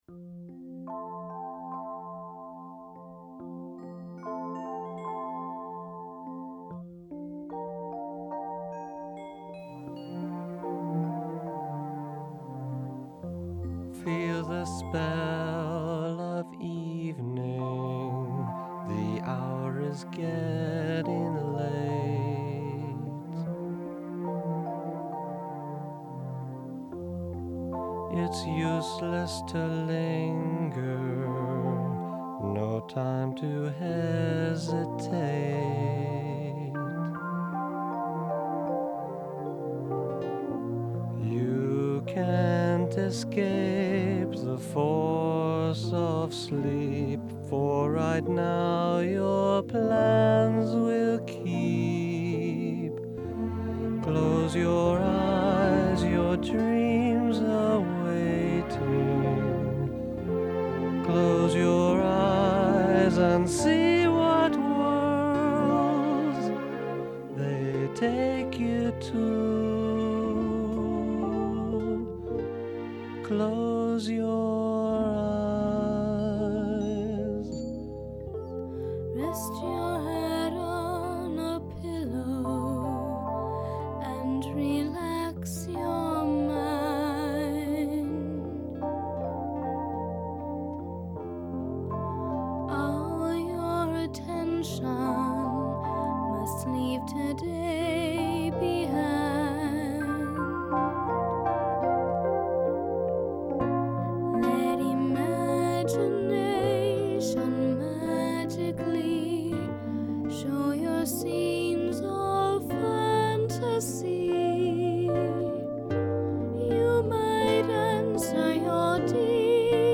tender lullaby